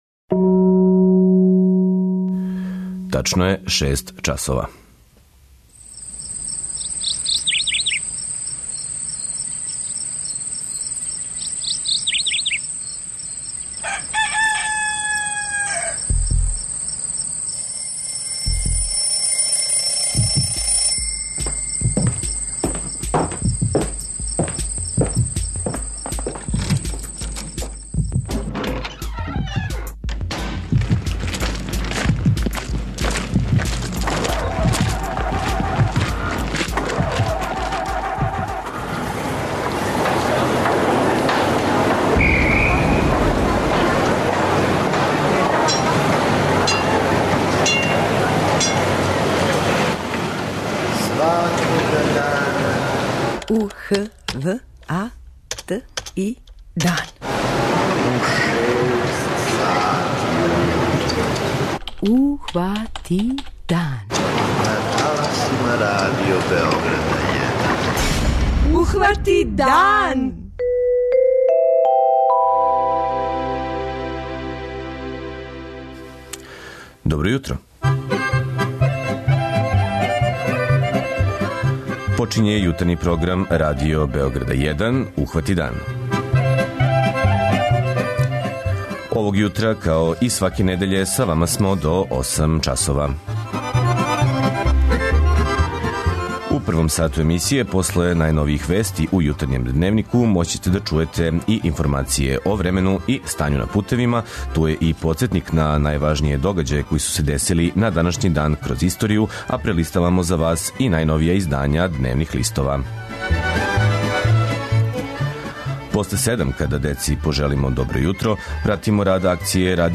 У оквиру емисије емитујемо: 06:03 Јутарњи дневник; 06:35 Догодило се на данашњи дан; 07:00 Вести; 07:05 Добро јутро децо
О томе разговарамо са државном секретарком у Министарству трговине, туризма и телекомуникација Татјаном Матић.